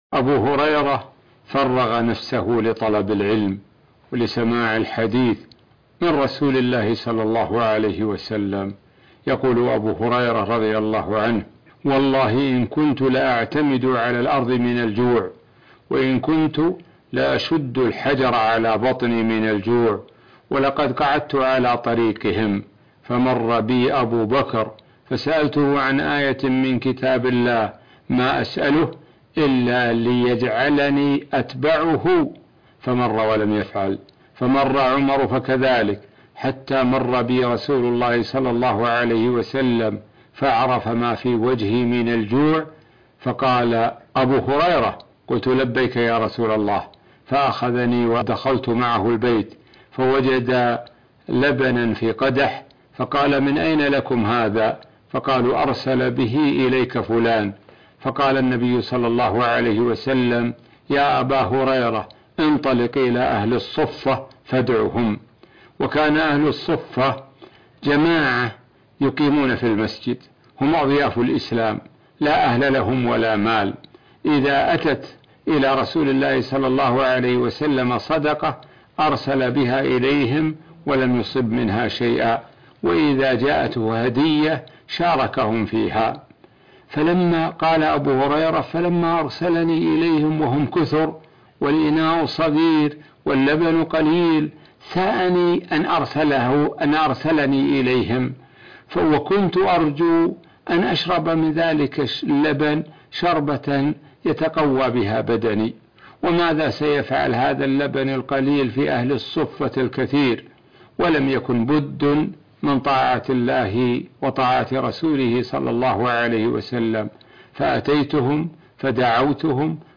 عنوان المادة الأيام الخالية الشيخ د سعد الشثري يتحدث عن الصحابي الجليل أبو هريرة رضي الله عنه